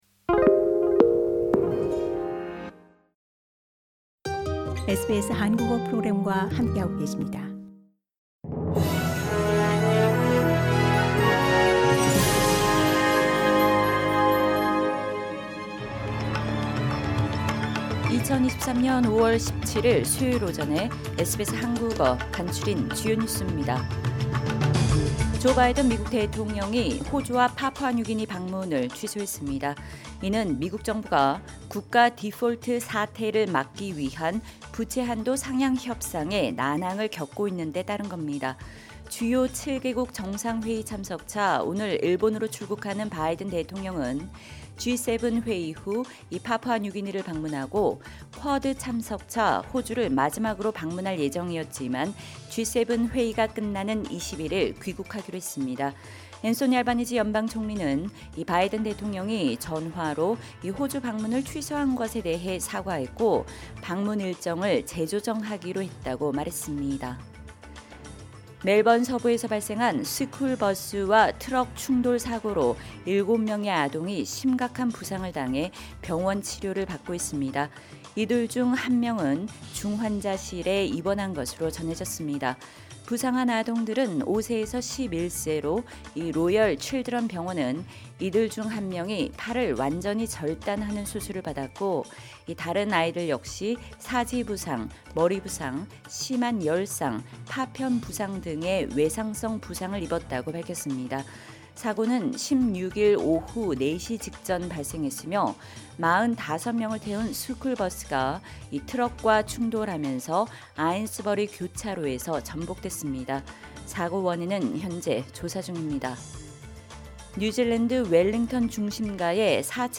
2023년 5월 17일 수요일 아침 SBS 한국어 간추린 주요 뉴스입니다.